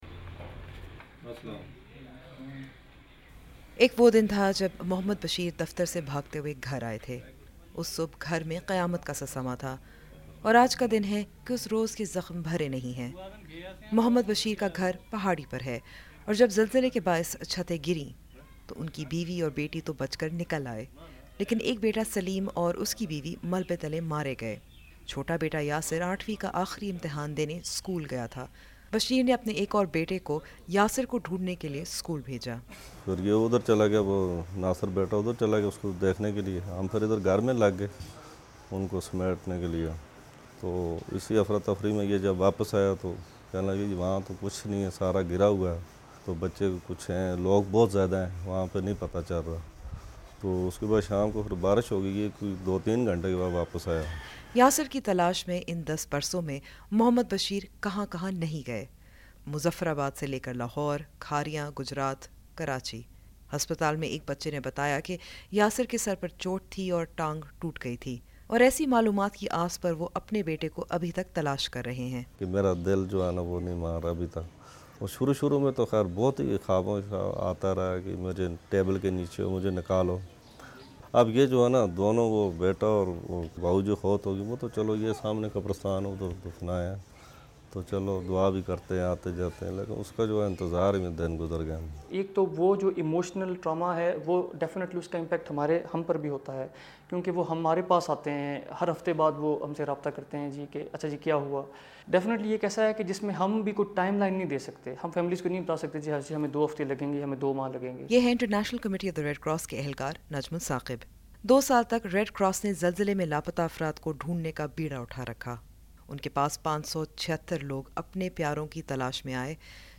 رپورٹ